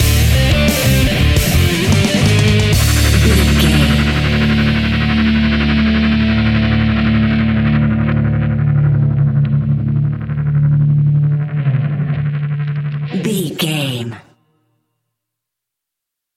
Epic / Action
Fast paced
Aeolian/Minor
D
hard rock
heavy metal
distortion
rock guitars
Rock Bass
Rock Drums
heavy drums
distorted guitars
hammond organ